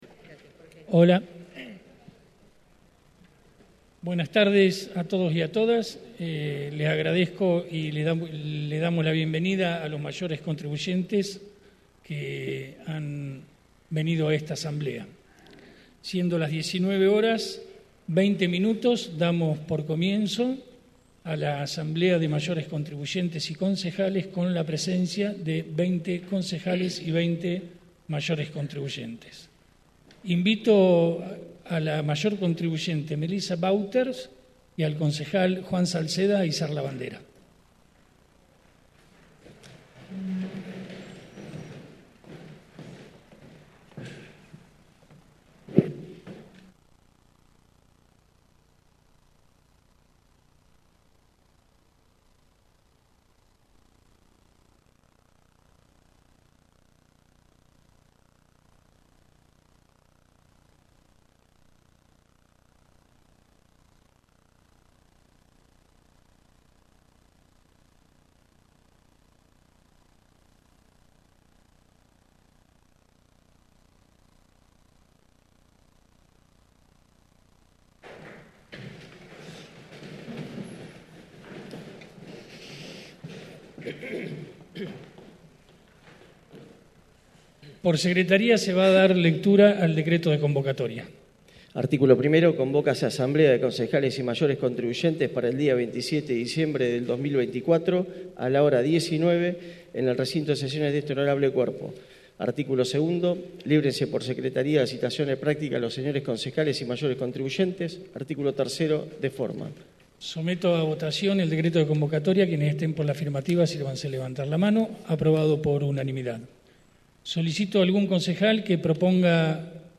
Audios de sesiones
Asamblea de Concejales y Mayores Contribuyentes